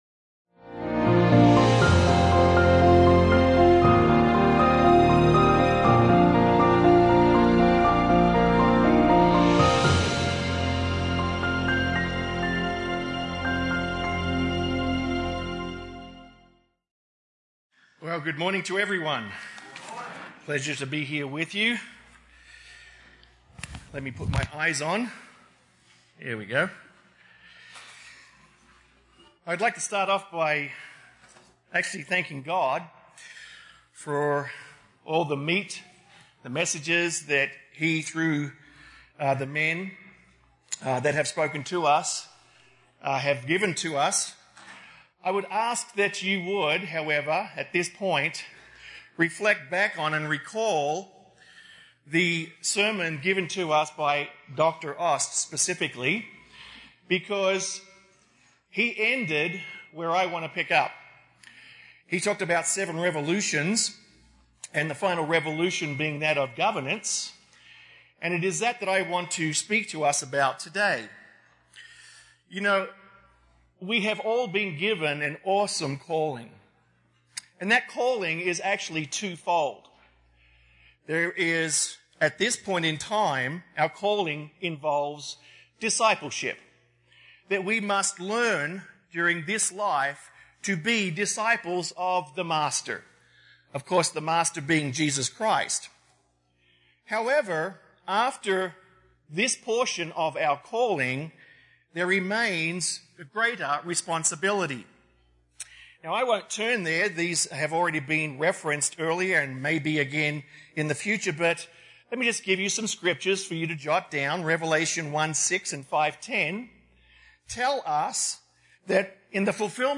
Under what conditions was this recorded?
This sermon was given at the Steamboat Springs, Colorado 2016 Feast site.